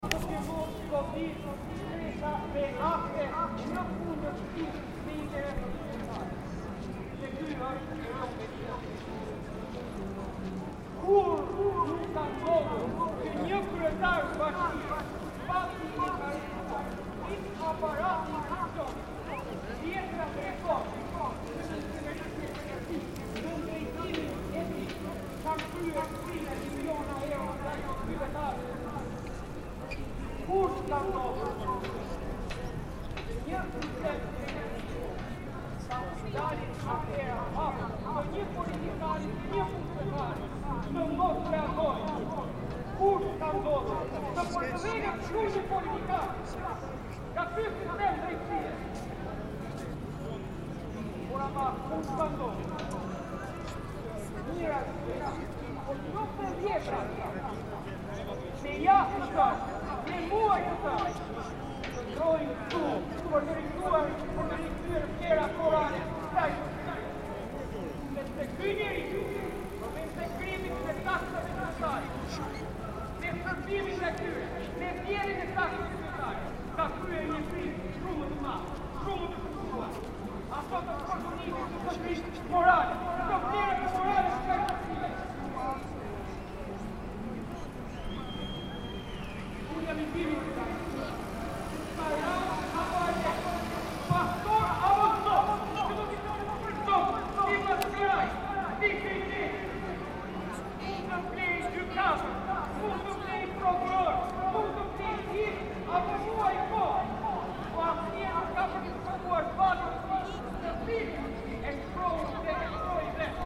Captured in Skanderbeg Square, in the heart of Tirana, this recording showcases the soundscape of a protest organized by the opposition party, the Democratic Party of Albania.
In the recording, the voice of the individual speaking into the microphone during the protest in front of Tirana City Hall reverberates off the nearby museum, opera house, and Hotel Tirana surrounding the square. The preparations for the fair and the speeches happening in the square are also included in this recording, contributing to the intricate soundscape of the area.